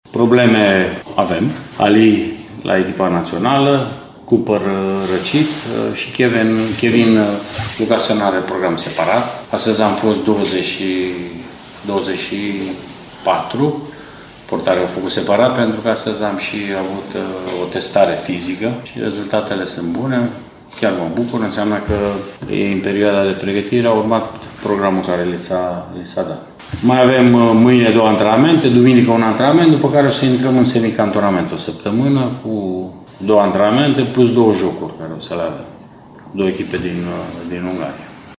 Rednic nu a oferit nume când a fost întrebat despre eventuale viitoare transferuri, dar a vorbit despre problemele de lot: